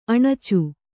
♪ aṇacu